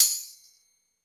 Percs
REDD PERC (27).wav